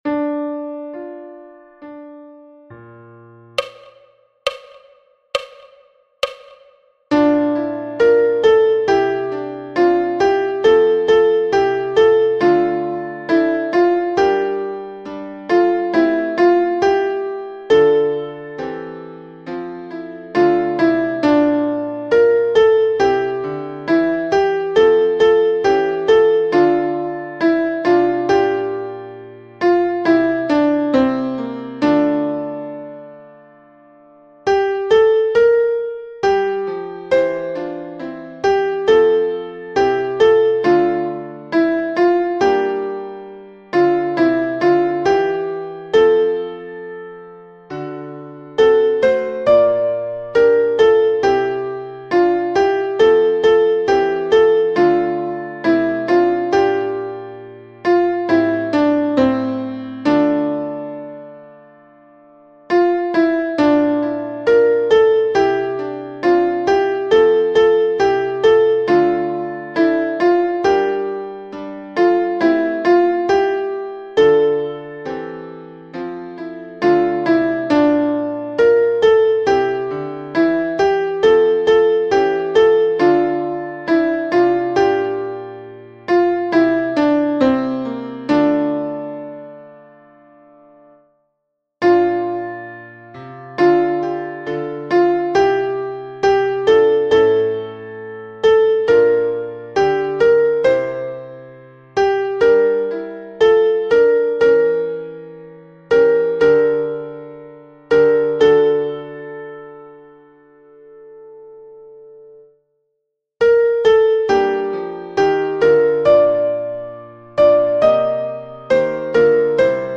night-sop.mp3